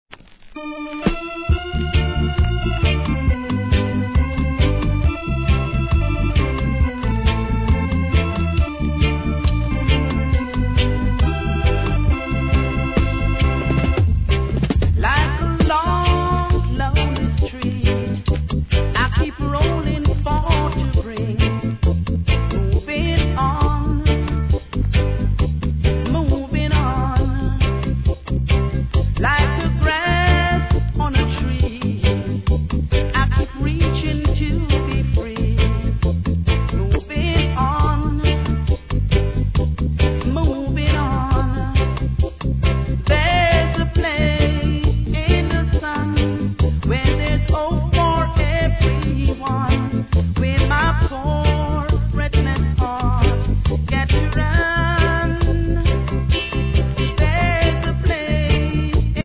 1. REGGAE